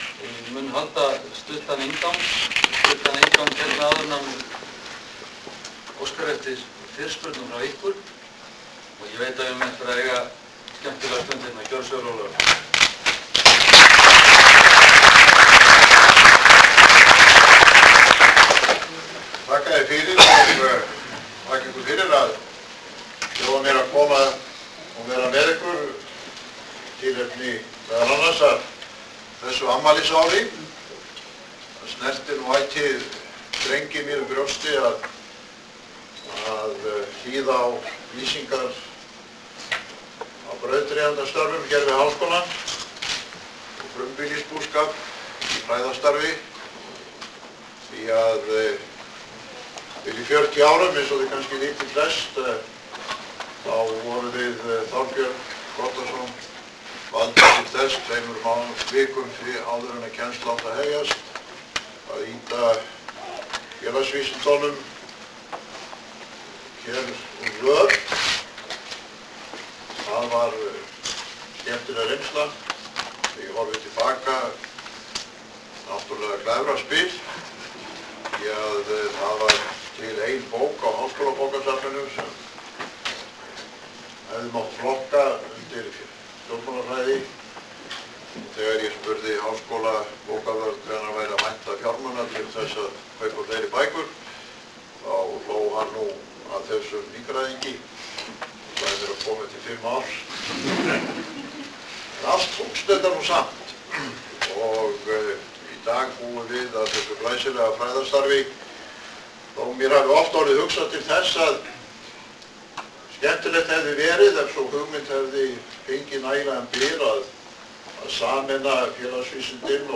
Forseti flytur erindi og svarar fyrirspurnum á hádegisfundi Viðskiptafræðideildar Háskóla Íslands í tengslum við tíu ára afmæli MBA náms við skólann.